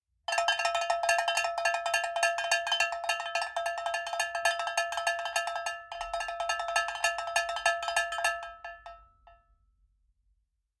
Звуки колокольчика
Звон колокольчика на животных